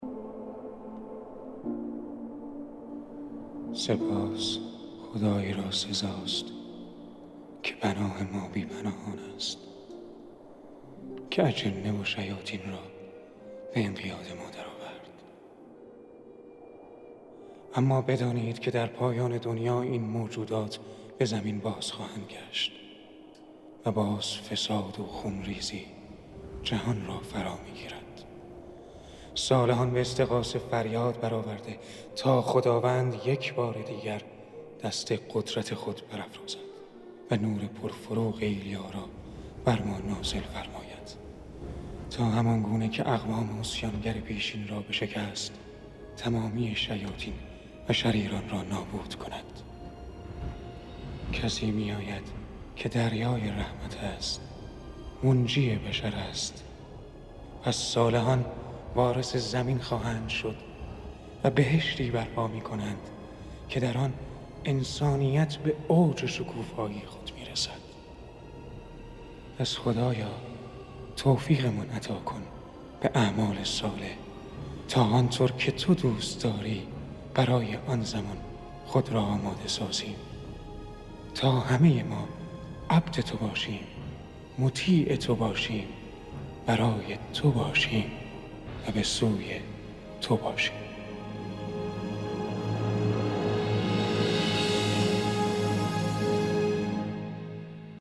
آهنگ تیتراژ با صدای